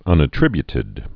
(ŭnə-trĭby-tĭd)